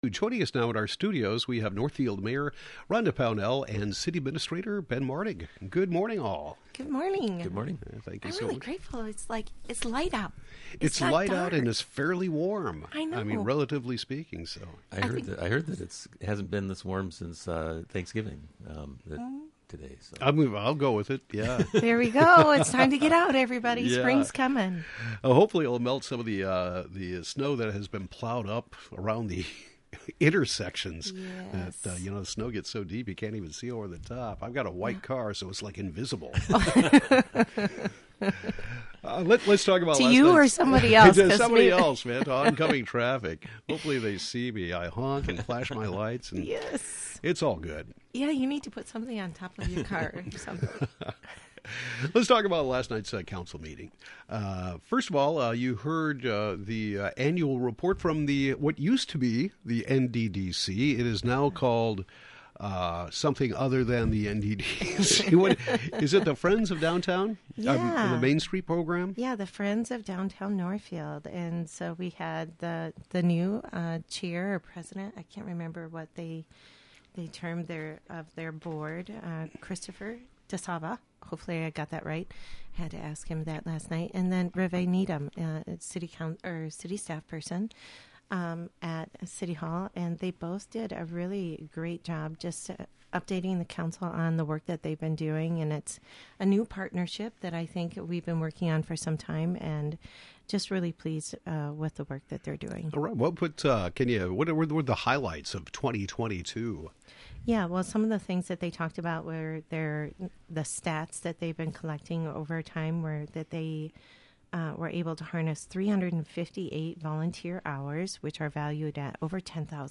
Northfield Mayor Rhonda Pownell and City Administrator Ben Martig discuss the February 7 City Council meeting.